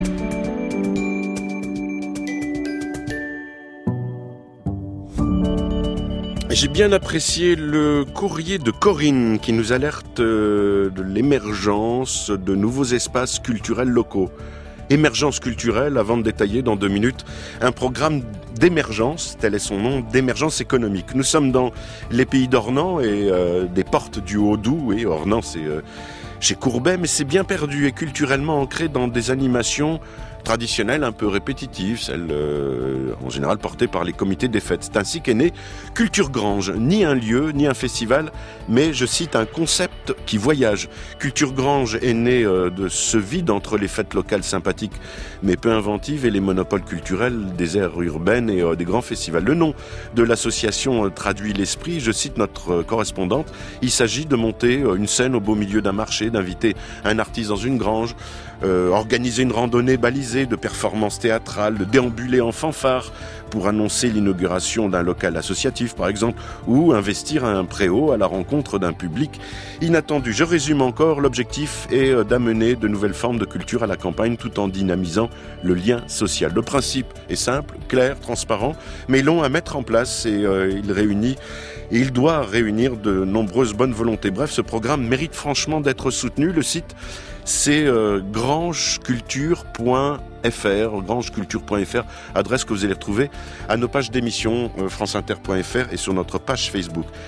C’est tout à fait par hasard que nous avons eu le plaisir ce 03 octobre d’entendre parler de Grange culture sur France inter dans l’émission “Carnets de campagne” de Philippe Bertrand.